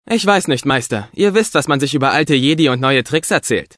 For the game, Effective Media engaged the very same dubbing actors who lend their voices to the characters in the film in order to guarantee that the original flair of the film is maintained on your console at home ...
Anakin Skywalker: